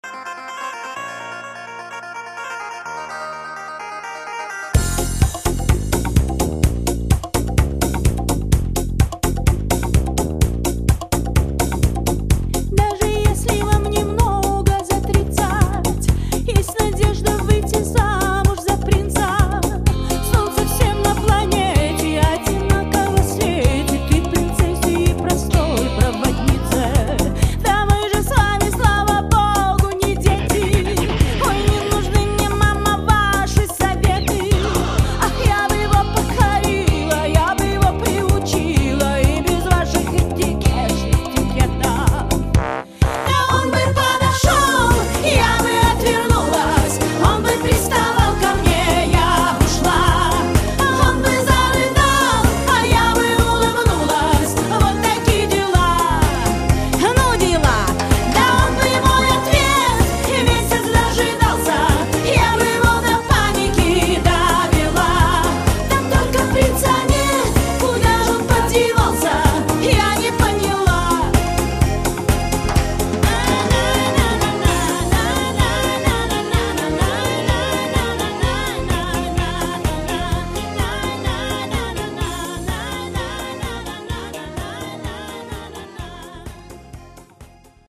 Listen to our Russian Musicians below: